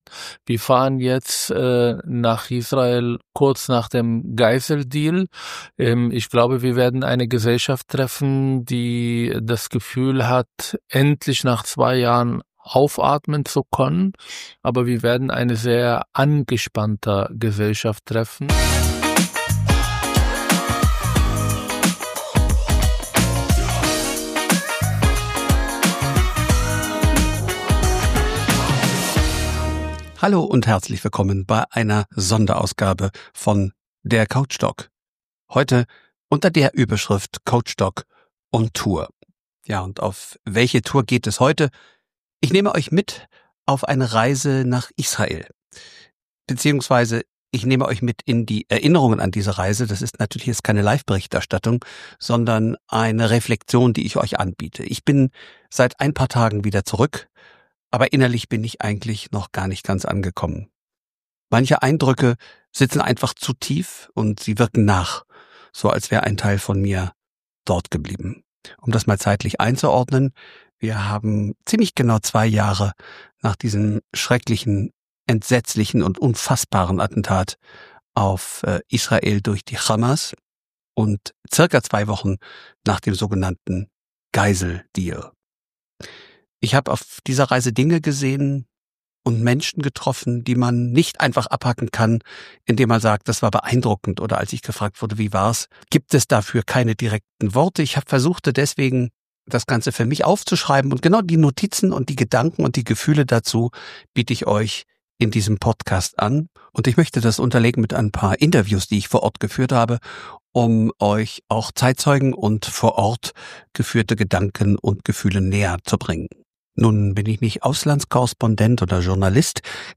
Ein ruhiger, eindrücklicher Bericht über Angst, Stärke und die Kraft, Mensch zu bleiben.